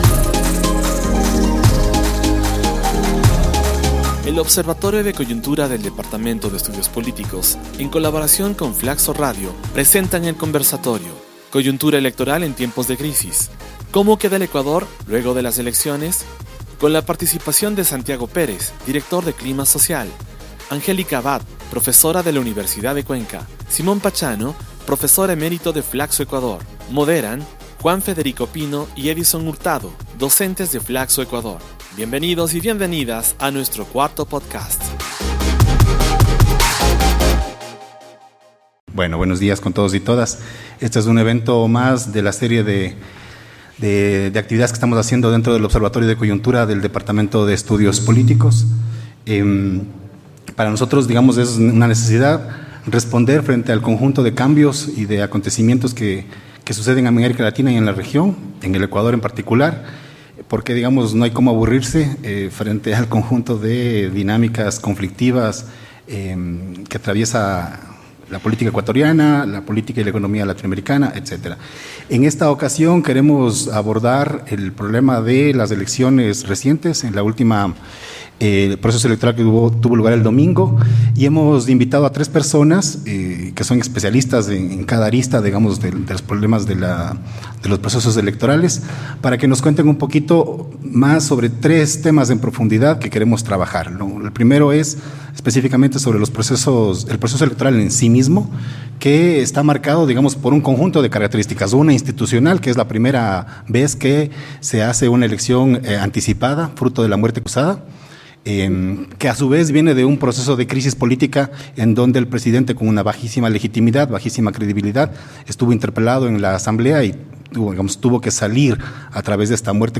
El Observatorio de Coyuntura del Departamento de Estudios Políticos, en colaboración con FLACSO Radio, realizaron el conversatorio titulado: Coyuntura electoral en tiempos de crisis ¿Cómo queda el Ecuador luego de las elecciones?